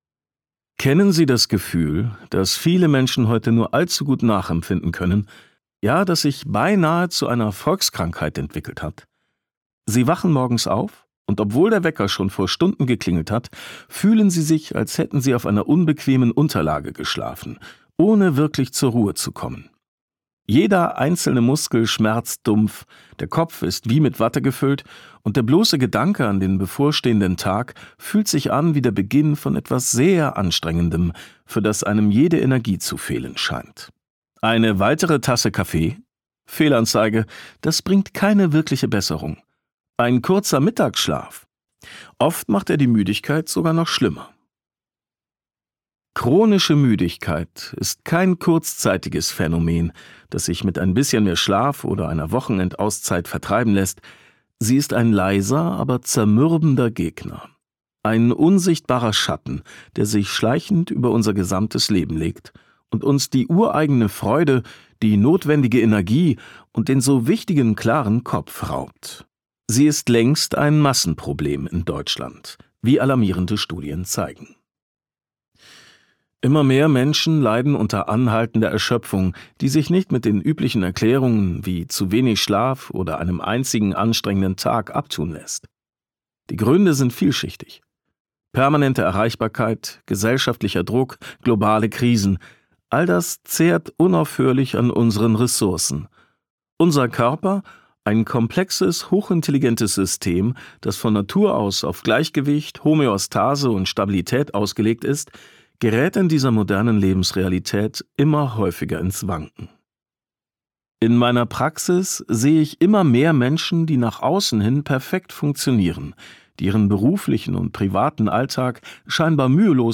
Ständig müde trotz viel Schlaf? Das neue Gesundheitsratgeber-Hörbuch des Bestsellerautors
Gekürzt Autorisierte, d.h. von Autor:innen und / oder Verlagen freigegebene, bearbeitete Fassung.